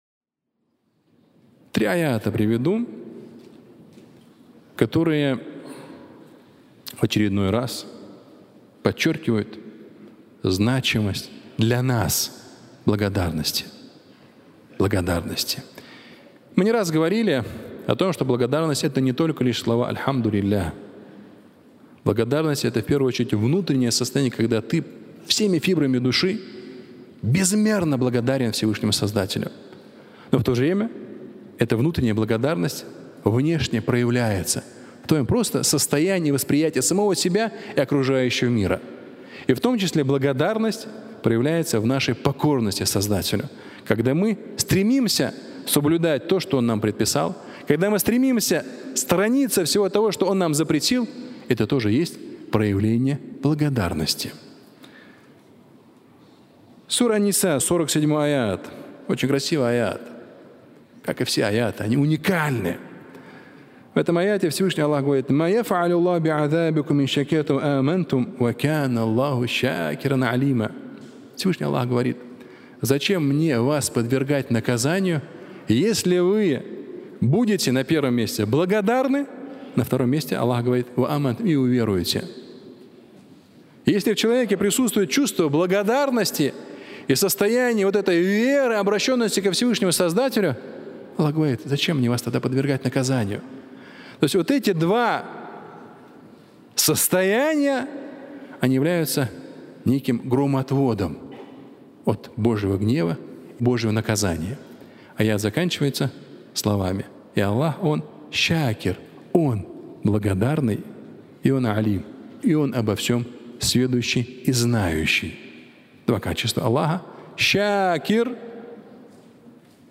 Три аята и благодарность (аудиолекция)